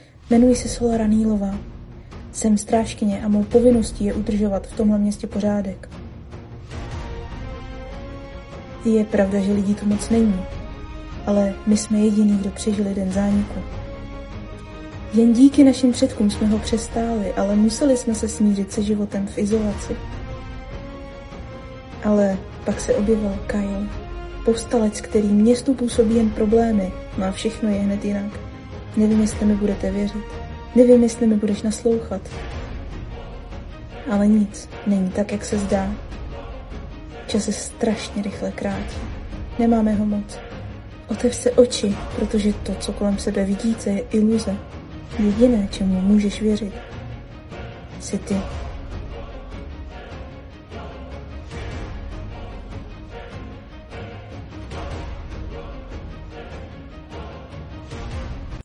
• Záznam bez šumu: Používáme vybavení a techniku pro čistý a jasný zvuk.
• voiceover videa pro podporu crowfundingu knihy Iluze